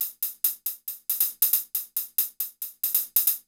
CL HH PAN -L.wav